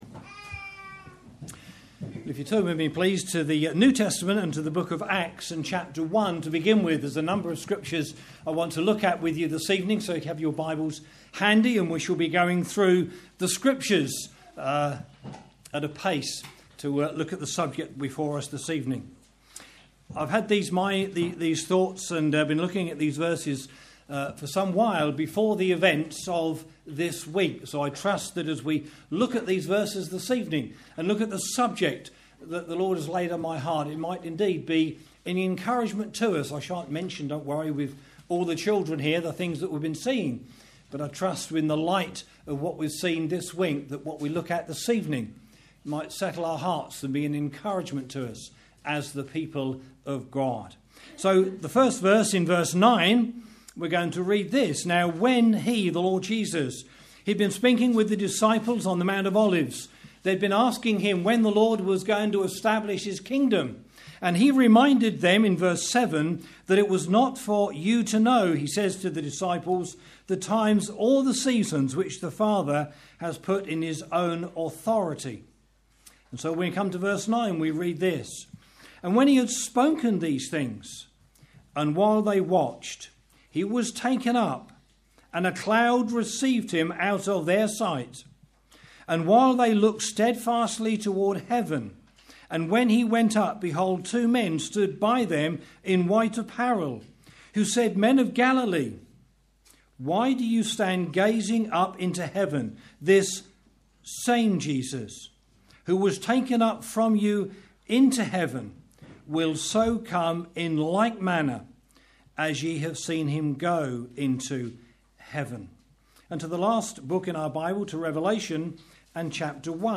A recording of our Regular Ministry Meeting Series.